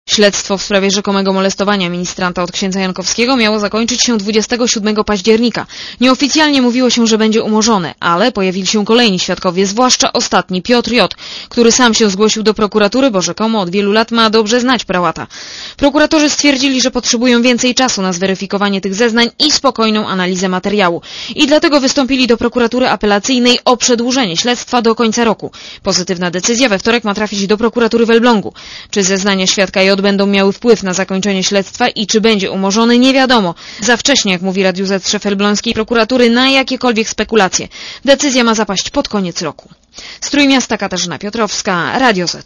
reporterki Radia ZET*